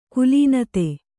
♪ kulīnate